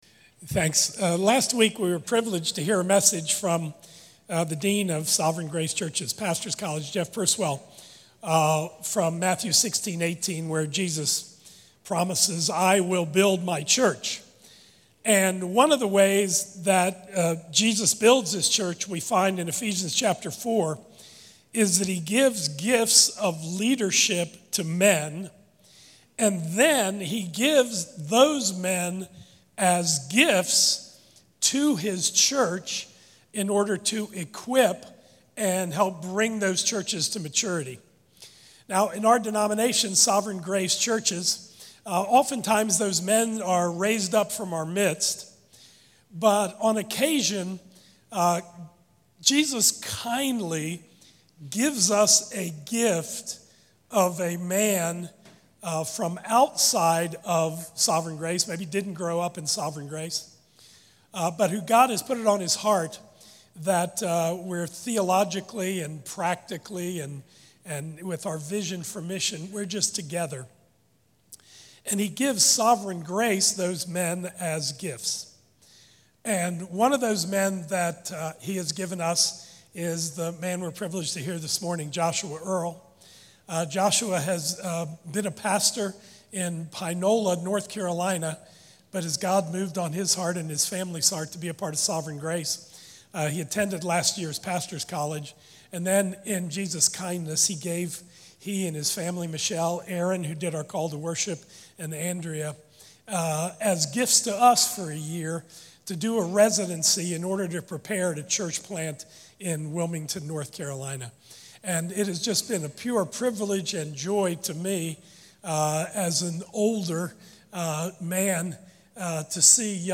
A message on Luke 7:36-50